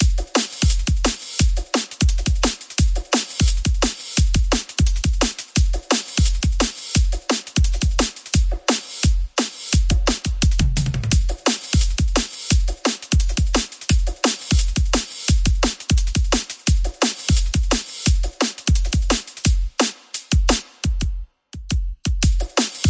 鼓乐队练习120BPM
描述：自定义鼓循环。3个Kontakt的工厂声音实例。低音鼓，汤姆鼓，小鼓。
Tag: 120 bpm Cinematic Loops Drum Loops 1.35 MB wav Key : Unknown